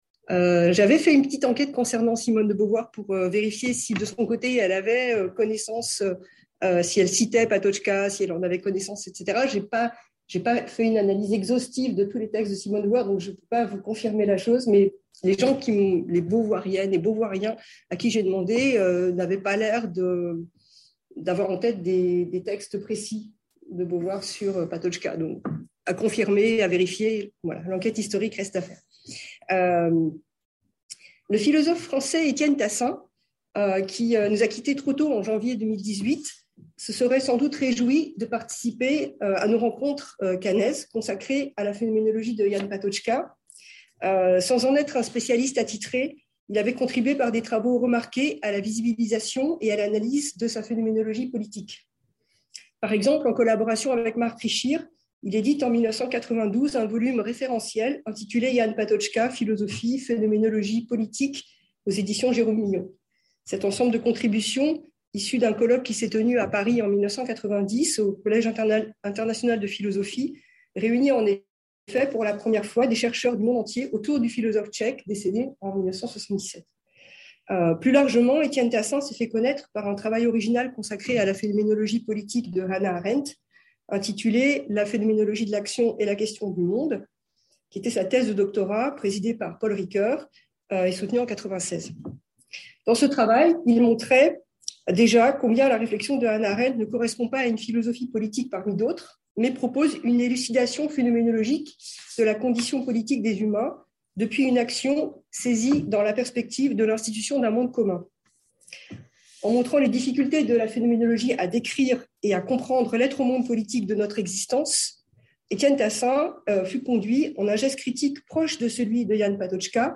Dans le cadre du colloque sur le philosophe tchèque Jan Patočka